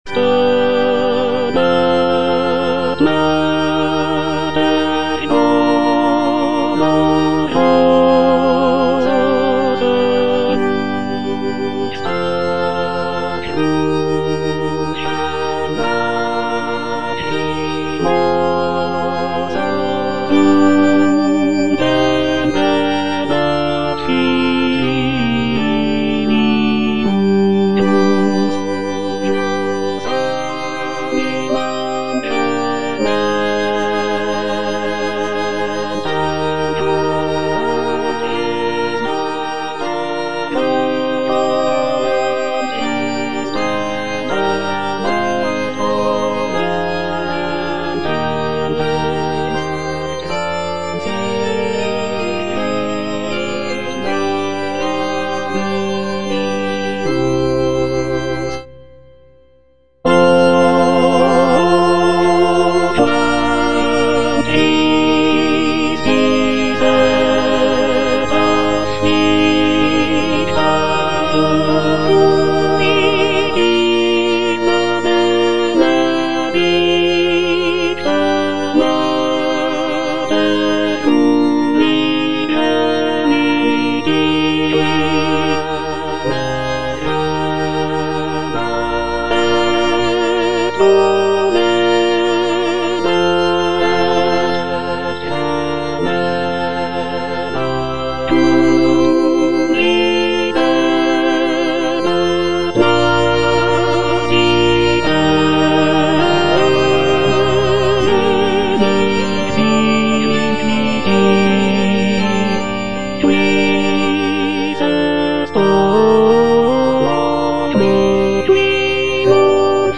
G.P. DA PALESTRINA - STABAT MATER Stabat Mater dolorosa (tenor I) (Emphasised voice and other voices) Ads stop: auto-stop
"Stabat Mater" by Giovanni Pierluigi da Palestrina is a sacred choral work that sets the text of the hymn Stabat Mater Dolorosa, which portrays the Virgin Mary standing at the foot of the cross during the crucifixion of Jesus. Composed in the late 16th century, Palestrina's setting of the Stabat Mater is known for its emotional depth, intricate polyphonic textures, and expressive harmonies.